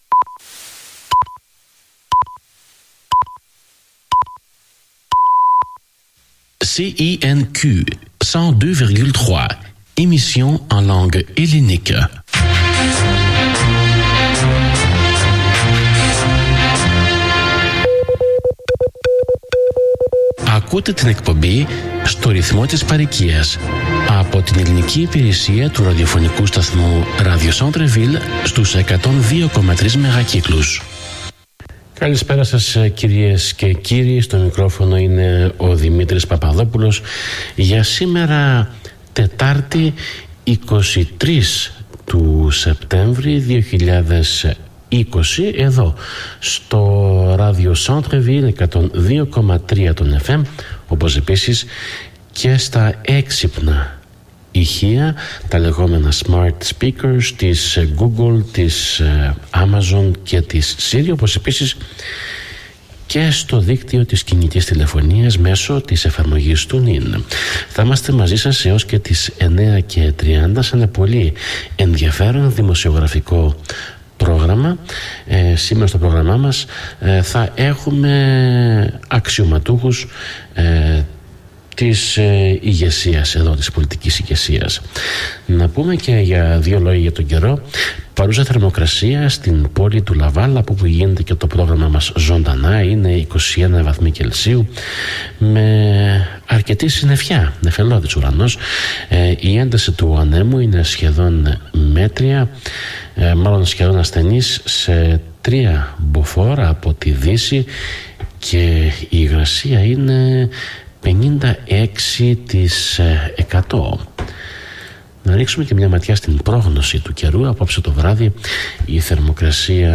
Ειδήσεις Συνέντευξη